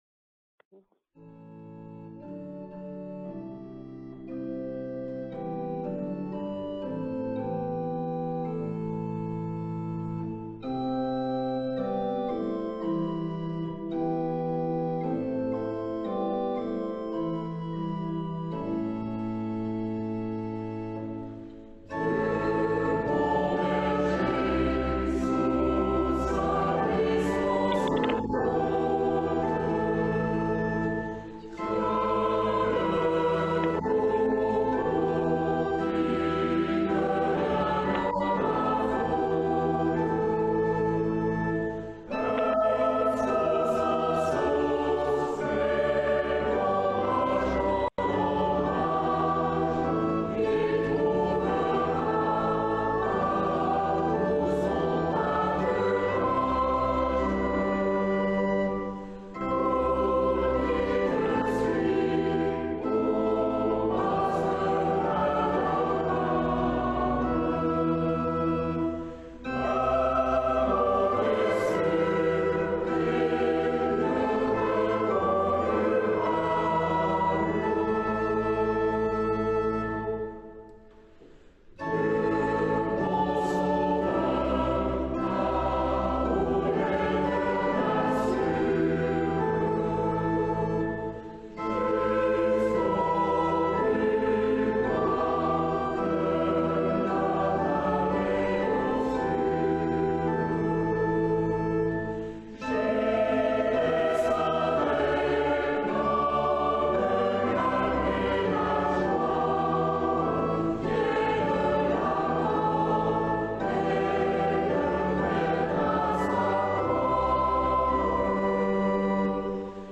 Culte de l’ascension : 21 mai 2020